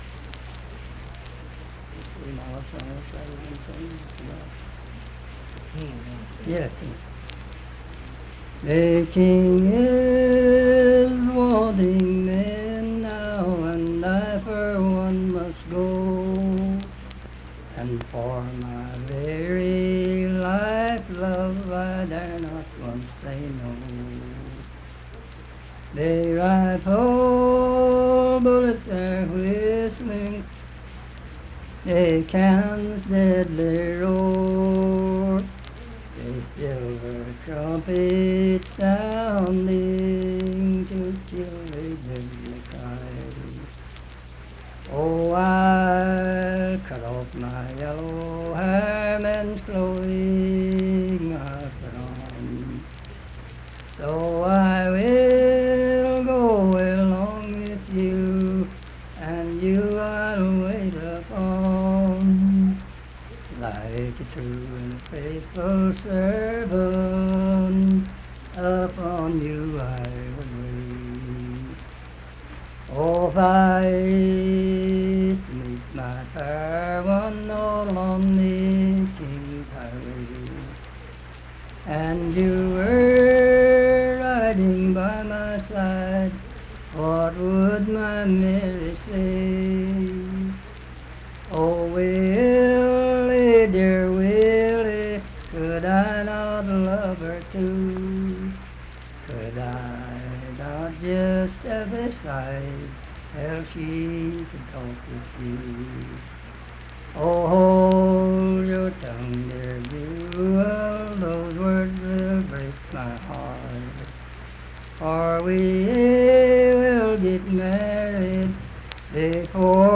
Unaccompanied vocal music
Verse-refrain, 7(4).
Voice (sung)
Harts (W. Va.), Lincoln County (W. Va.)